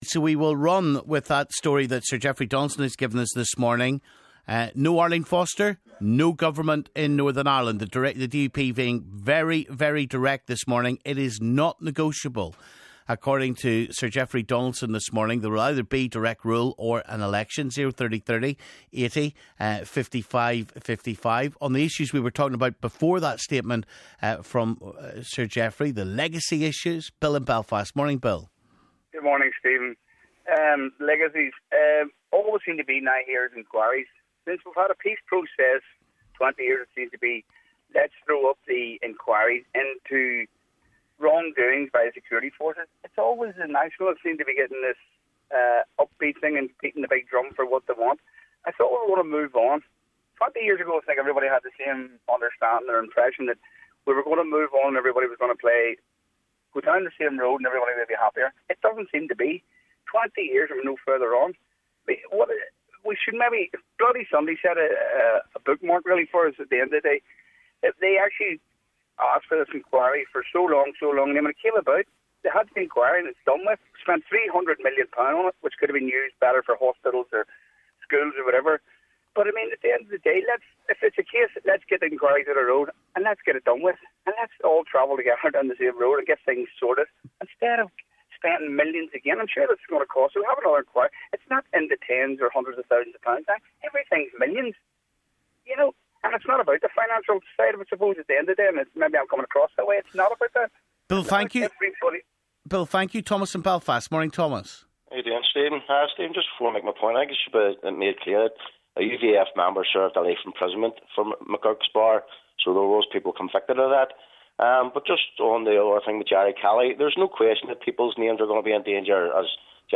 Callers and commentators react to breaking news on DUP red line in Stormont talks